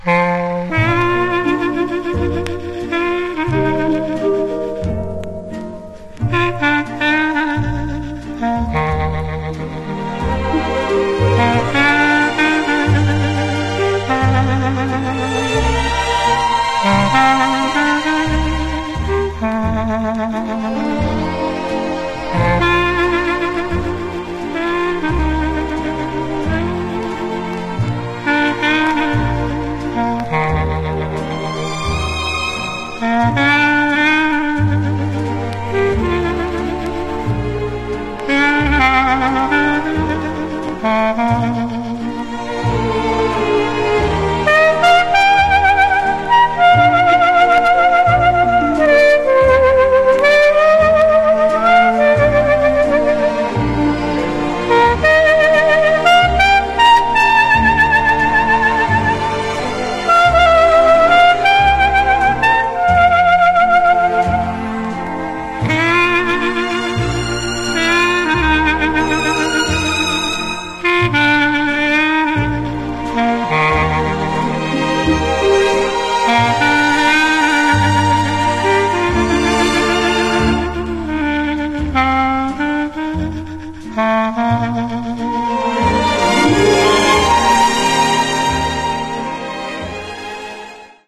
Genre: Pop Instrumentals